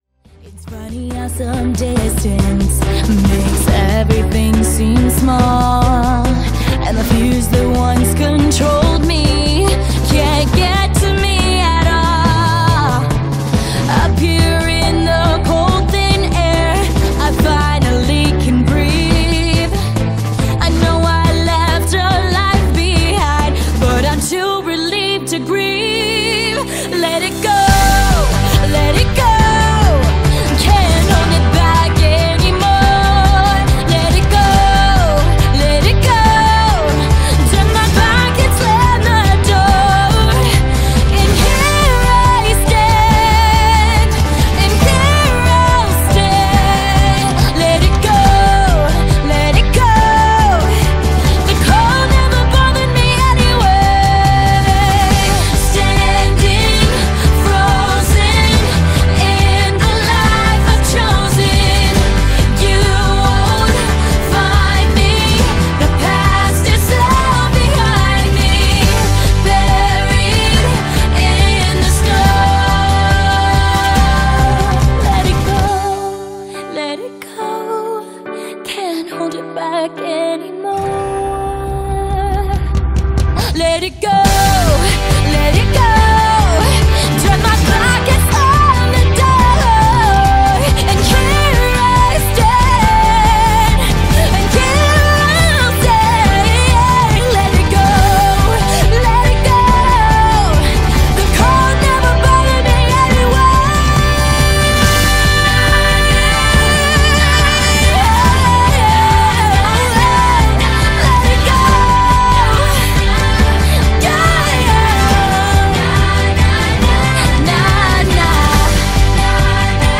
BPM70-280
Audio QualityMusic Cut